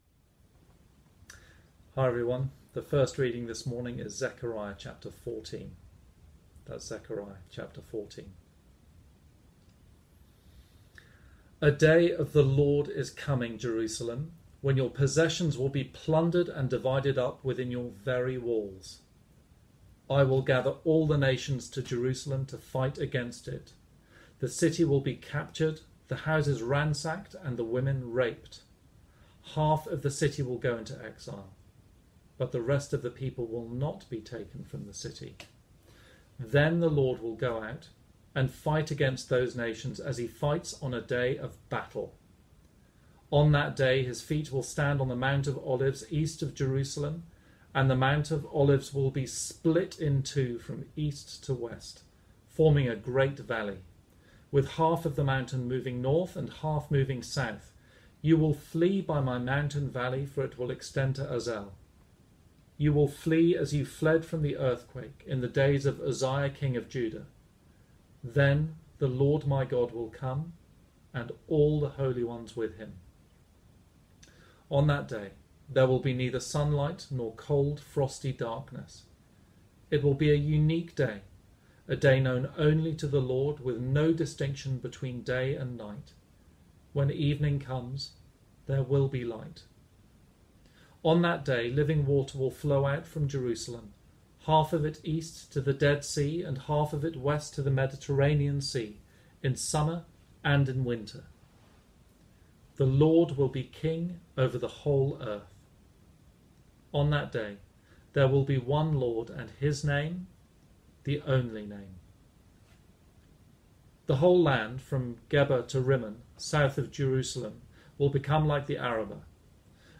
This is the final sermon in our series 'Disappointed?' looking at Zechariah. This sermon focused on Zechariah 14 and had this outline:
Sunday-Service-Ruin-Restoration-audio-clipped.mp3